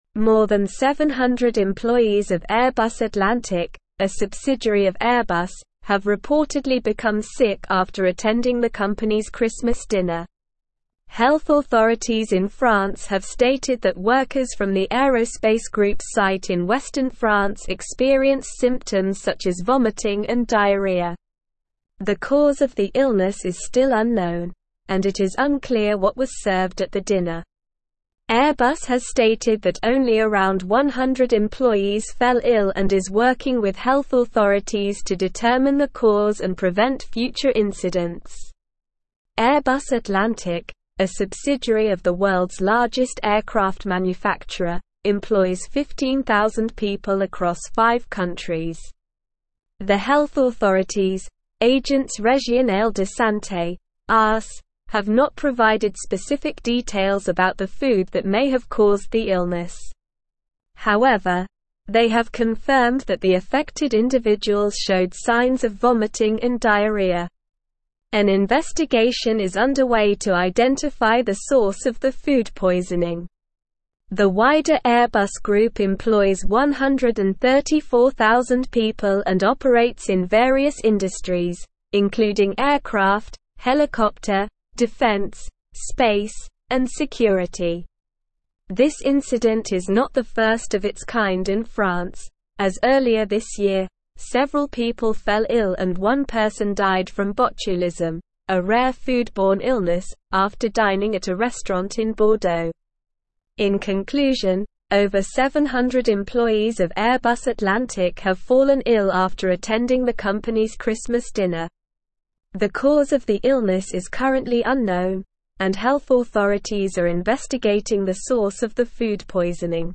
Slow
English-Newsroom-Advanced-SLOW-Reading-Over-700-Airbus-staff-fall-ill-after-Christmas-dinner.mp3